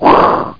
FLAME.mp3